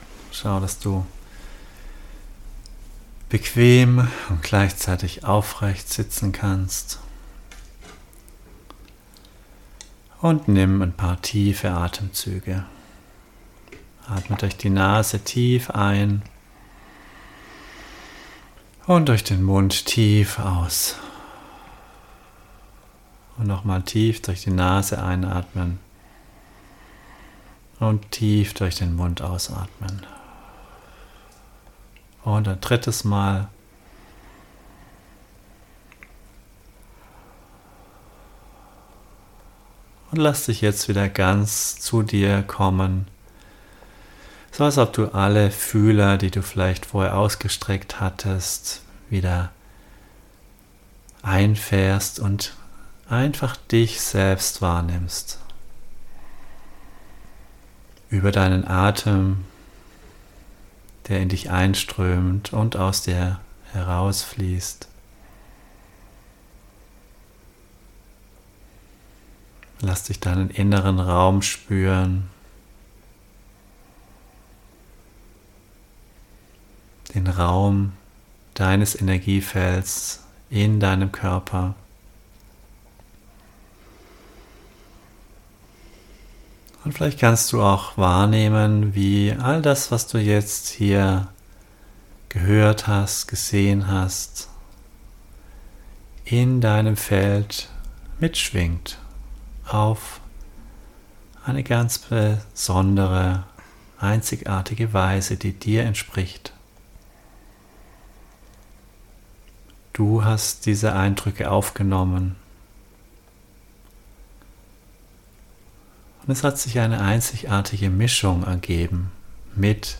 Meditation Dein Weg